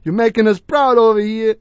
l_youmakenusproud.wav